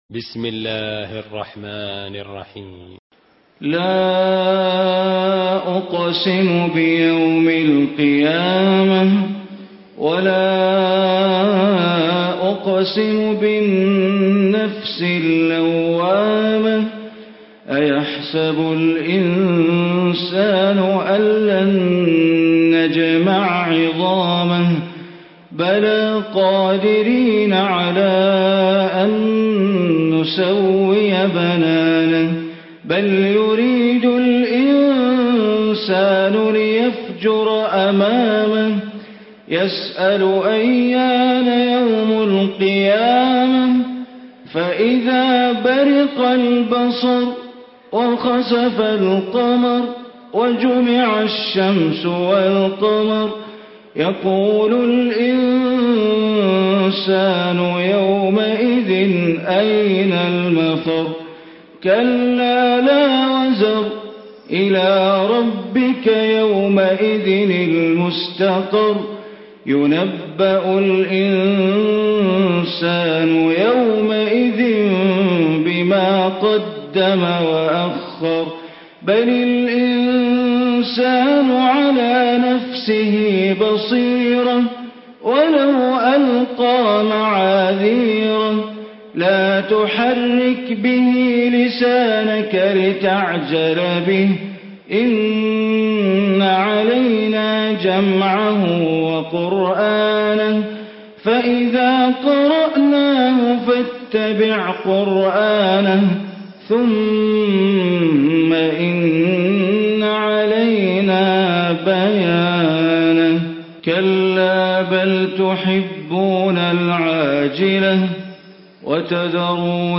Surah Qiyamah Recitation by Sheikh Bandar Baleela
Surah Qiyamah, listen online mp3 tilawat / recitation in Arabic recited by Imam e Kaaba Sheikh Bandar Baleela.